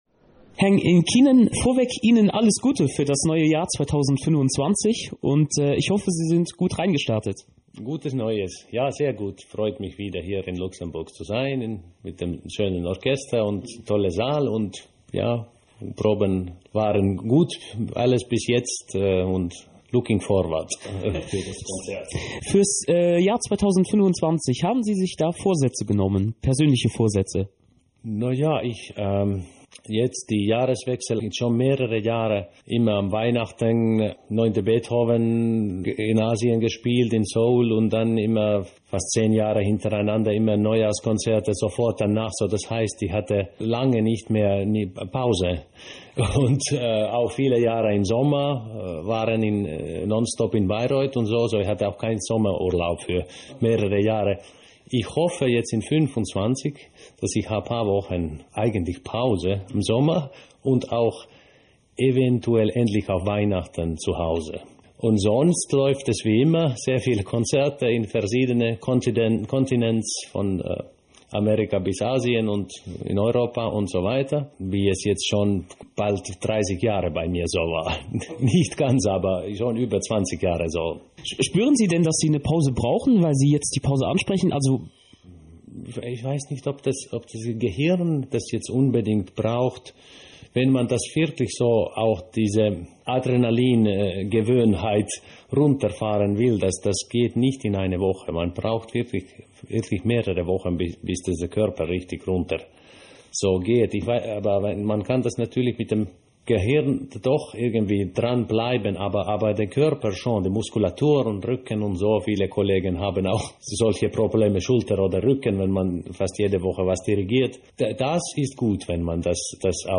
Interview with Radio 100,7
During the intermission of the New Year’s Concert broadcast with the Luxembourg Philharmonic Orchestra and soprano Elisabeth Teige, Radio 100,7 aired an interview with Pietari Inkinen. He spoke about the program, New Year’s resolutions, working with the Luxembourg Philharmonic, the orchestra’s distinctive sound, and much more.